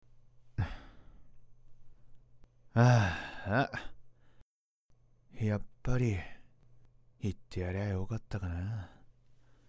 ・３つ年が上なので、やや大人びているところも
【サンプルボイス】